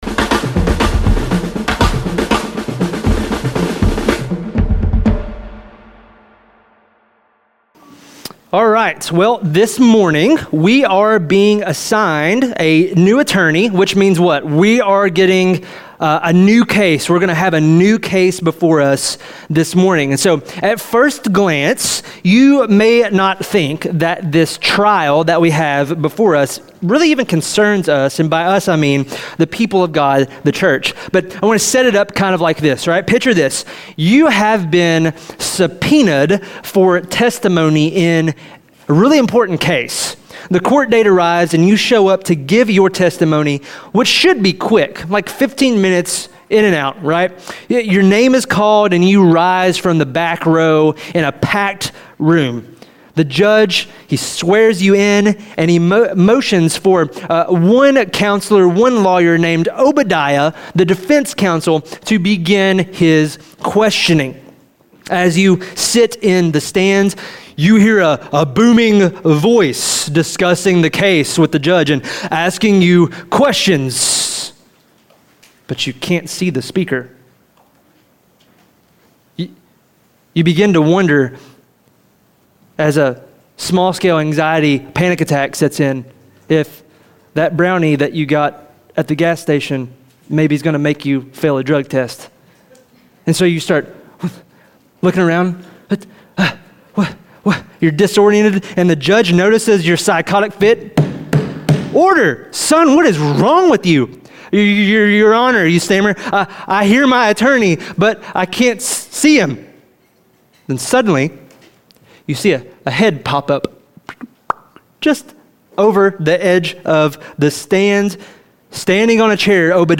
Sermons | Silver City Church